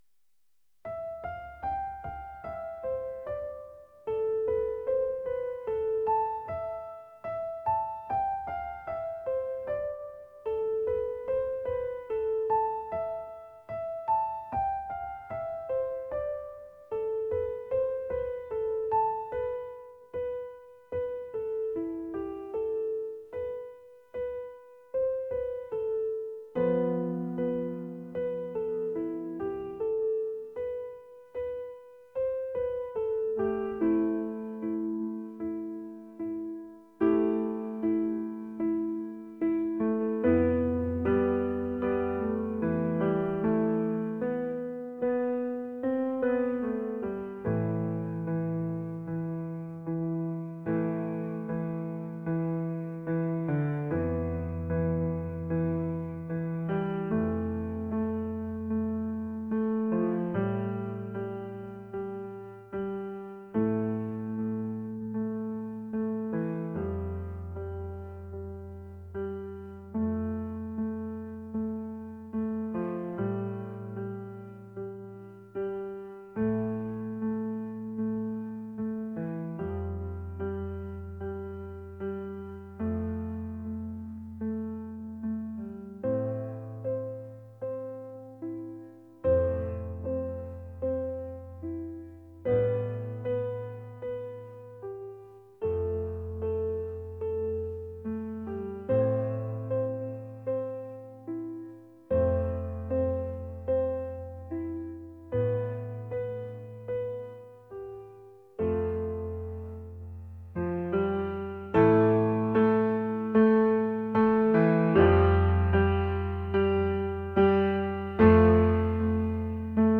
world | classical